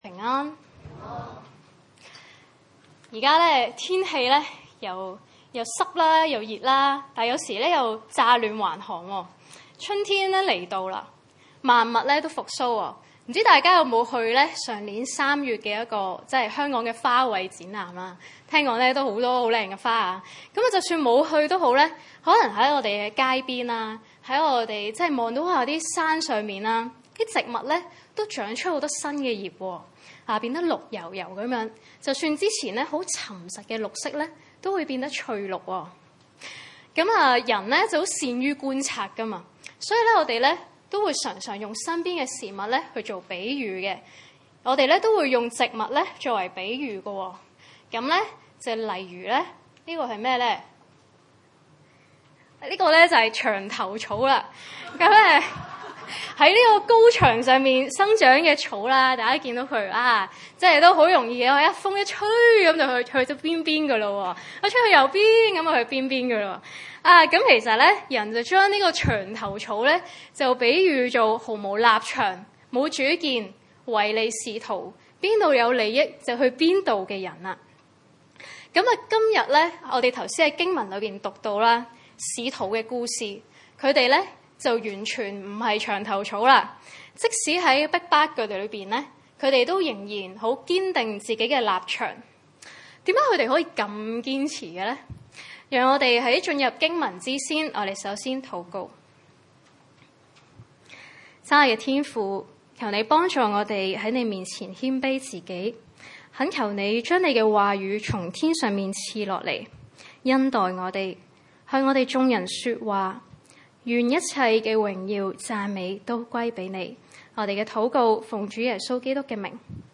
使徒行傳5:27-32 崇拜類別: 主日午堂崇拜 27帶到了，便叫使徒站在公會前；大祭司問他們說： 28我們不是嚴嚴的禁止你們，不可奉這名教訓人嗎？